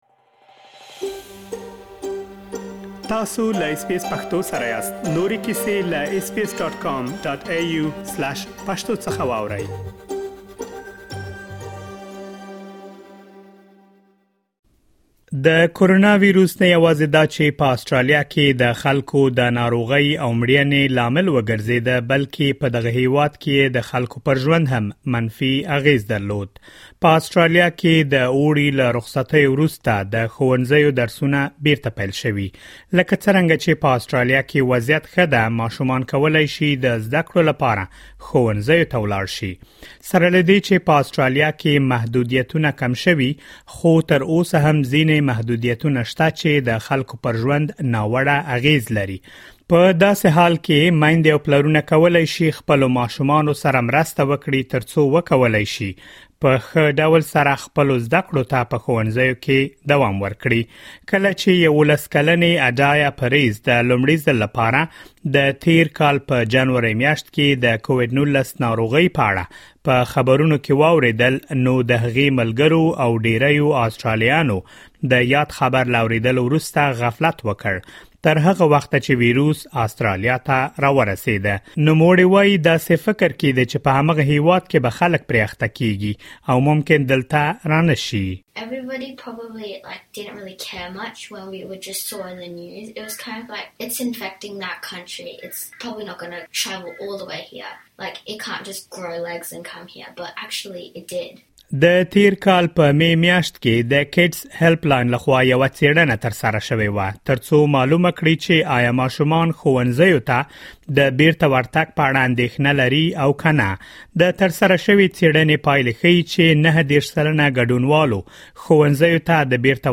تاسو ته رپوټ لرو په کوم کې چې پر ماشومانو د کرونا ويروس وبا اغيزو ته کتنه شوې.